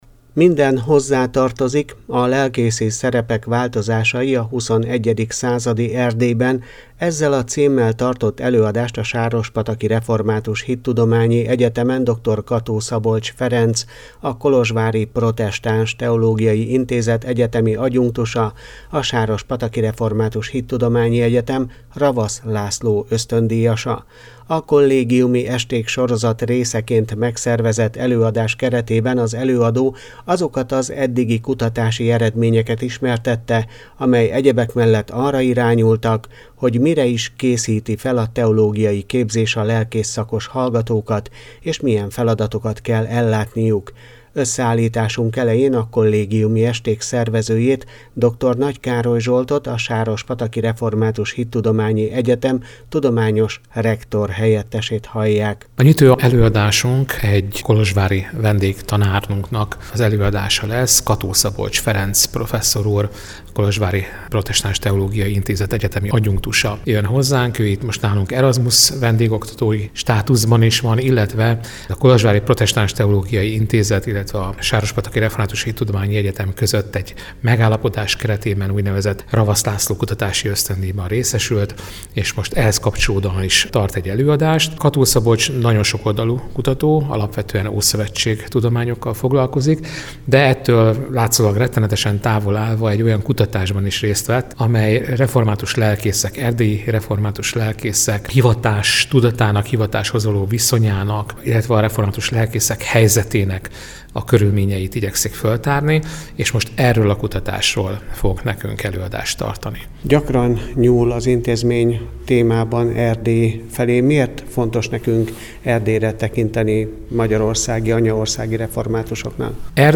„ Minden hozzátartozik…” - A lelkészi szerepek változásai a 21. századi Erdélyben. Ezzel a címmel tartott előadást a Sárospataki Református Hittudományi Egyetemen